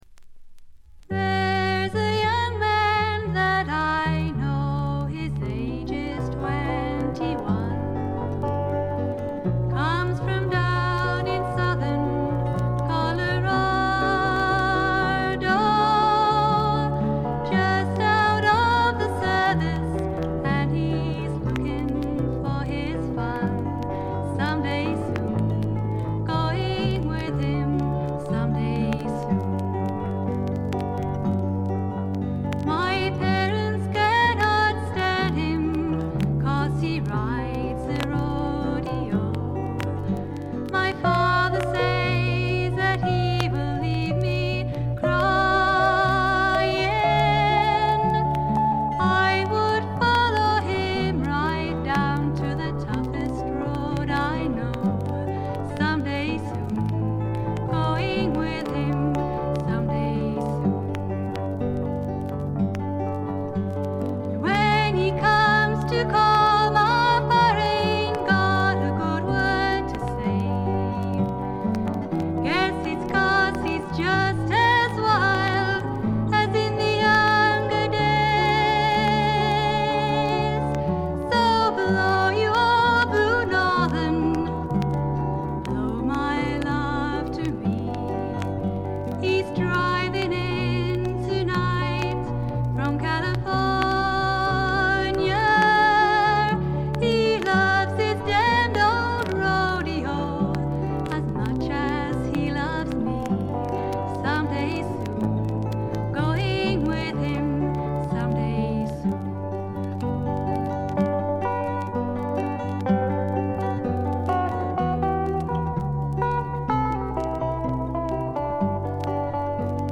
常時大きめのバックグラウンドノイズ。チリプチ、プツ音等も多め大きめ。
清楚でかわいらしくしっとりとした、実に魅力ある声の持ち主で、みんなこの透きとおるヴォイスにやられてしまうんですね。
試聴曲は現品からの取り込み音源です。
Guitar, Electric Guitar
Keyboard